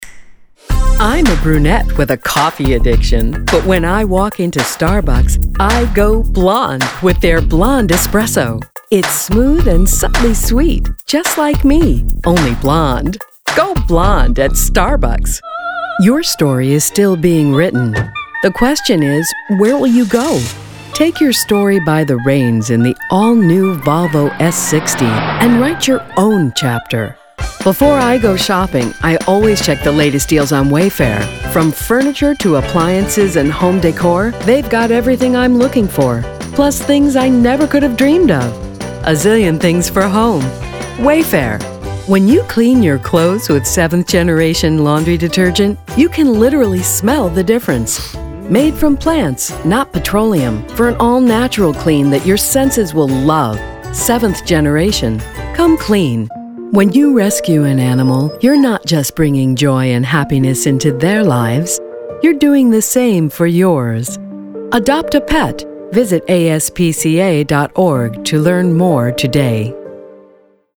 Commercial Demo
Young Adult
Middle Aged